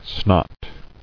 [snot]